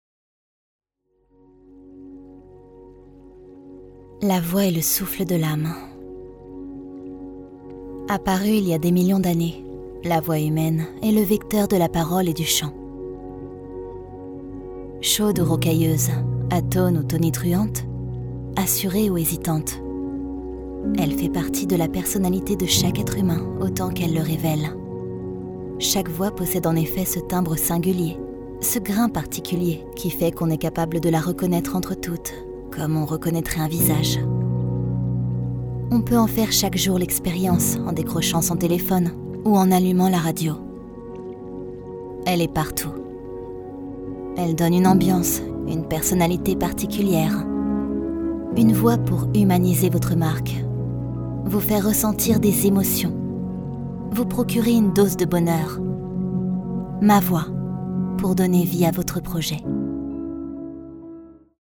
Voix off
NARRATION - INTRODUCING ME
Narratrice
3 - 90 ans - Mezzo-soprano Soprano